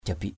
/ʥa-biʔ/ (t.) lõm; hốc hác. emaciated _____ Synonyms: luk l~K